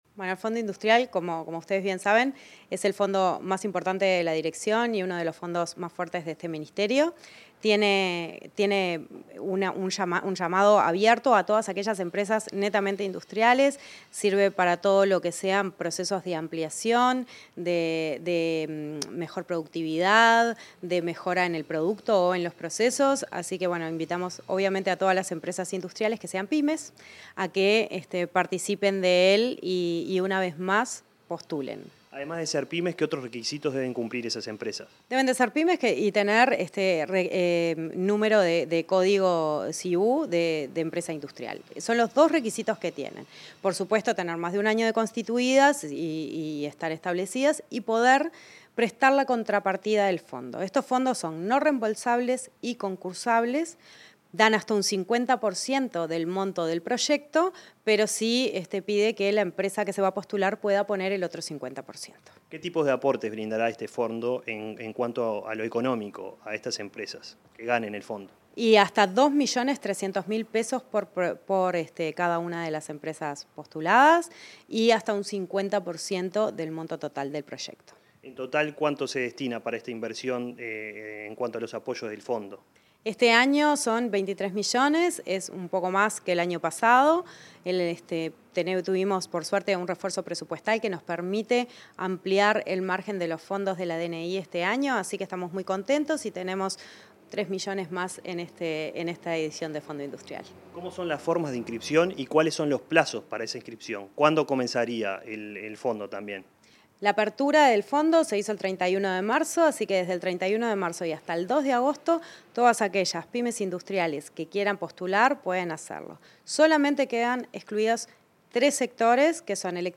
Entrevista a la directora nacional de Industrias, Susana Pecoy